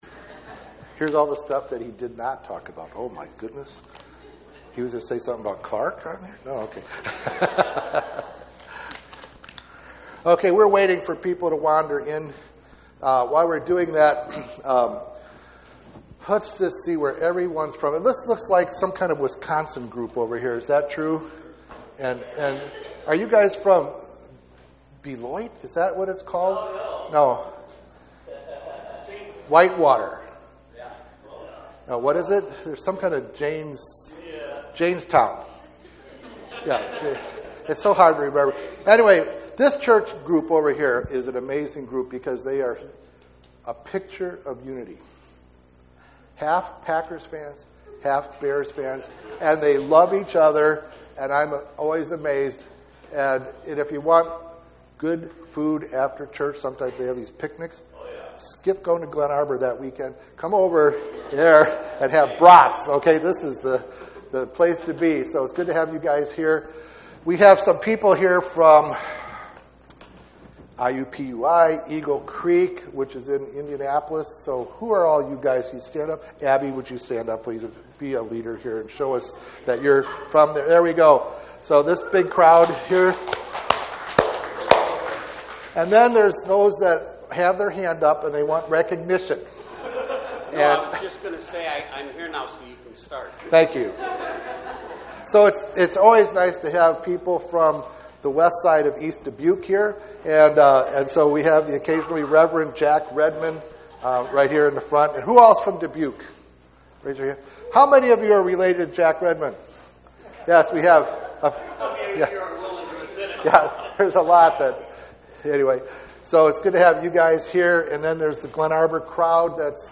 GCLI SERMON 0093.mp3